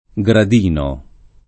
[ g rad & no ]